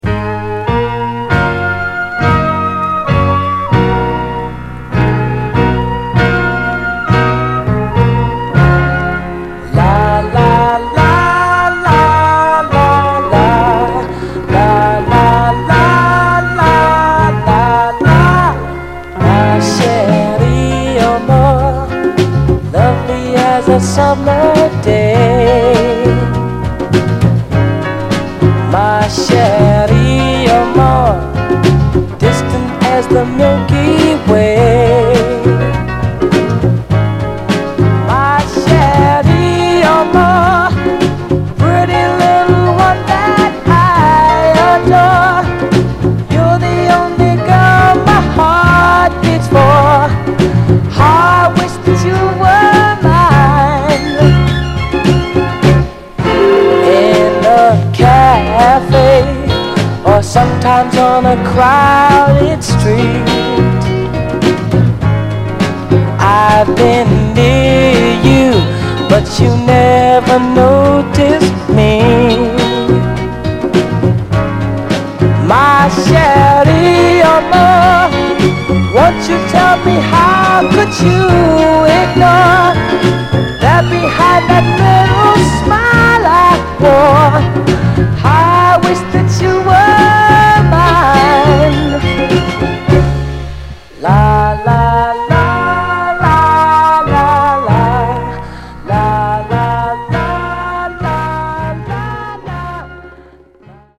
盤はA面エッジ中心に一部目立つ表面的なスレ、いくつか細かいヘアーラインキズありますが、グロスが残っておりプレイ良好です。
※試聴音源は実際にお送りする商品から録音したものです※